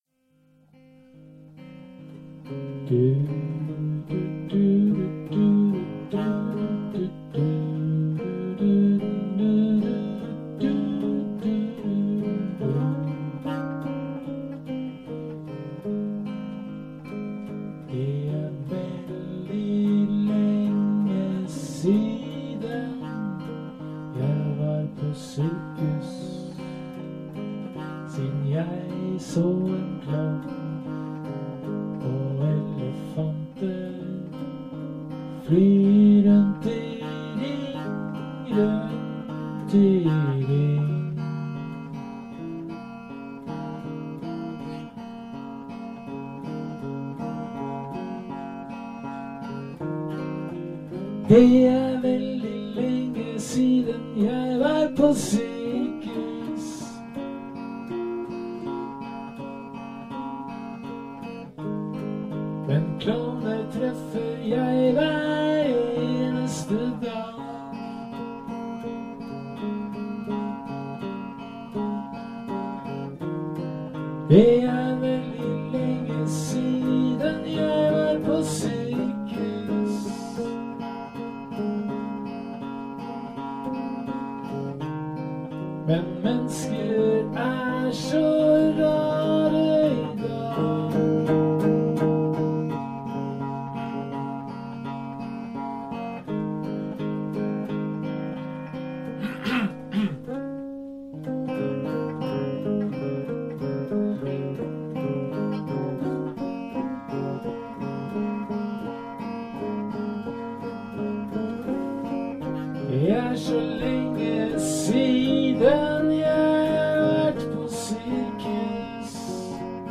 Ganska tidigt in på det nya året hade vi en tre timmars lång jam, där vi var väldigt inspirerade, och skapade mycket nytt.